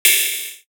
Tender Sizzle.wav